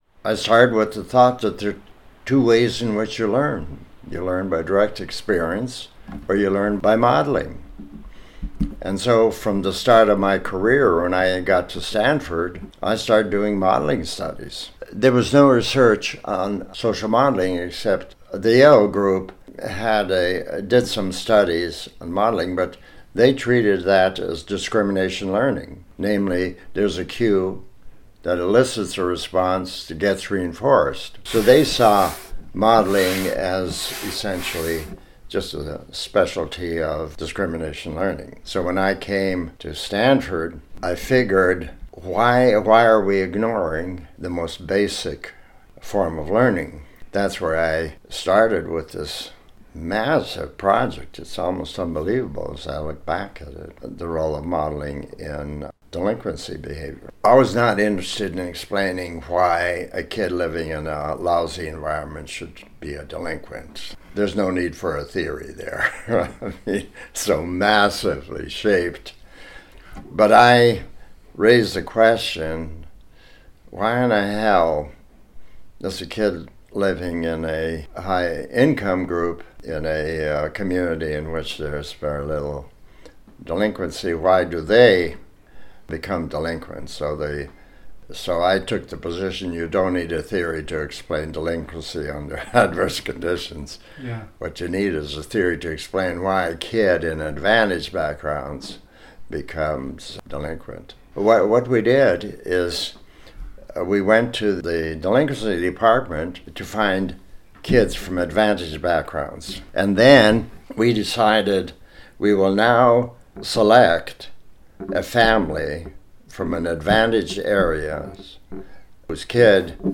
And so, it is with great pleasure that I share with you some tidbits, excerpts, and reflections from my conversations with Dr. Albert Bandura, the David Jordan Professor Emeritus of Social Science in Psychology at Stanford University.
Dr. Bandura recalled his first book and his early research on the origins of delinquent behavior in children: